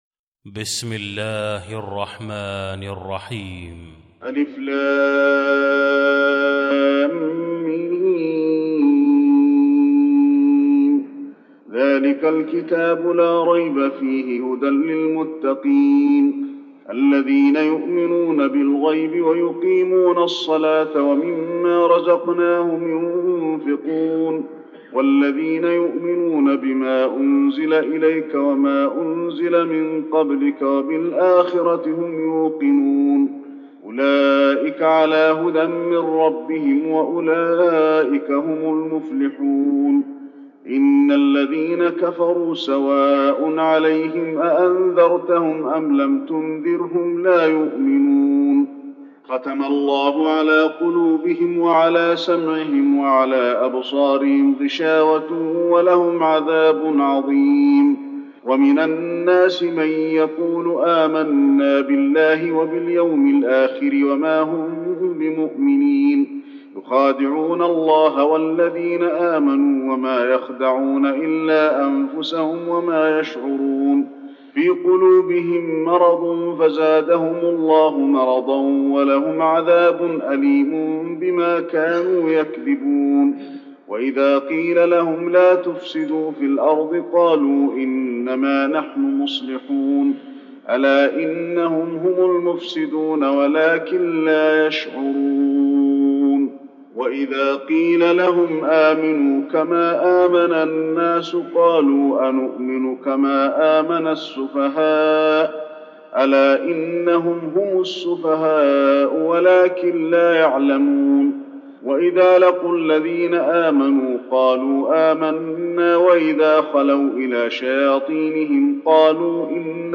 المكان: المسجد النبوي البقرة